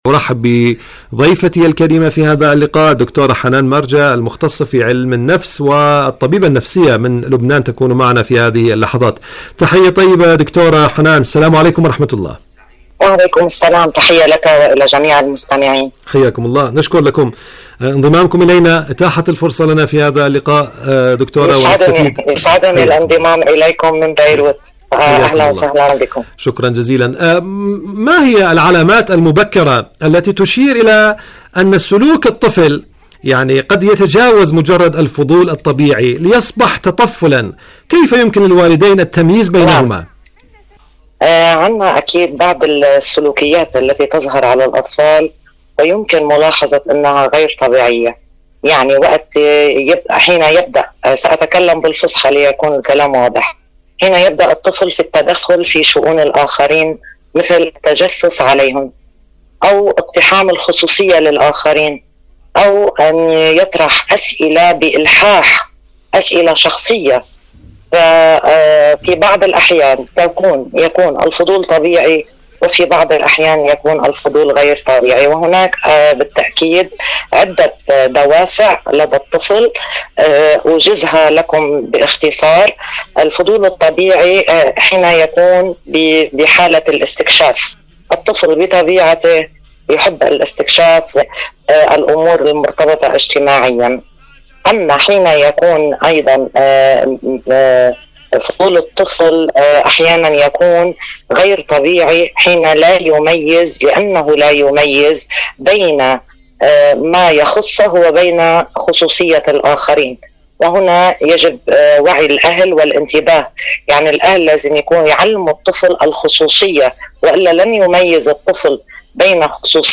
برنامج معكم على الهواء مقابلات إذاعية